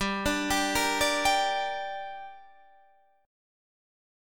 Gm chord